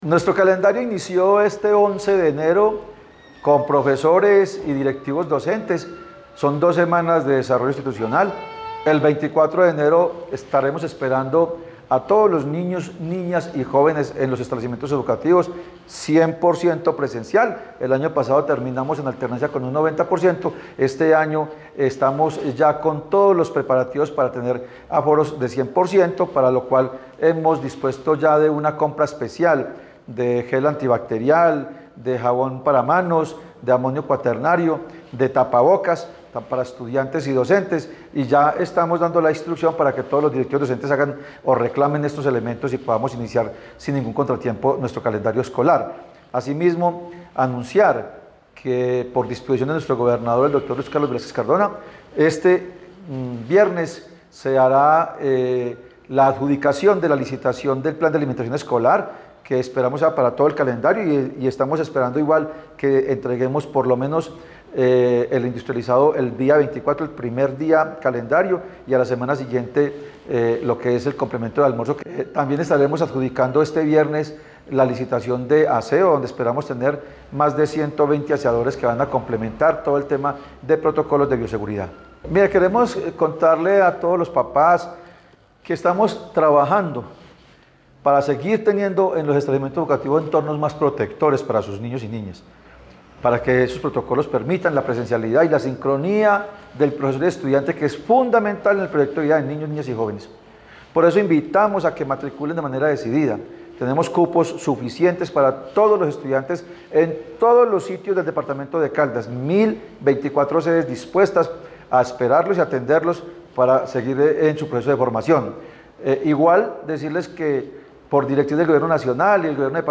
secretario_de_Educacion_de_Caldas_Fabio_Hernando_Arias_Orozco.mp3